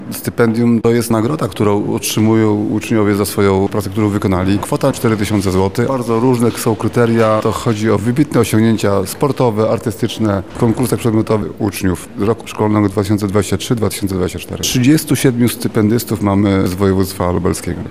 Więcej o uroczystości mówił nam Lubelski Kurator Oświaty, Tomasz Szabłowski.